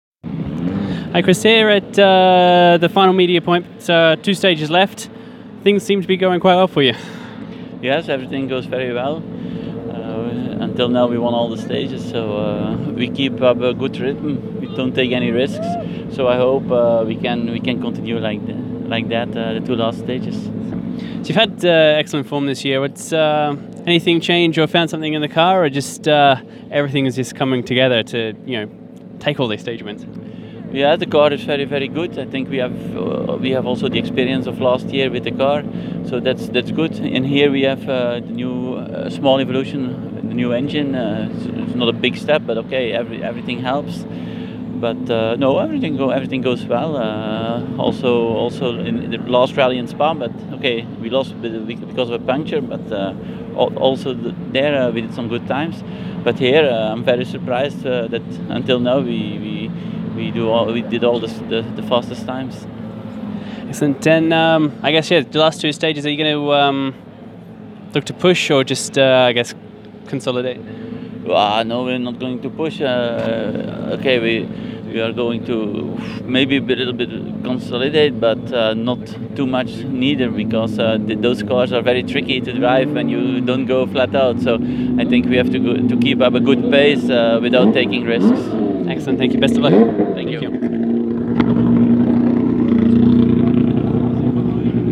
Driver reactions at the final service for the TAC Rally | RacerViews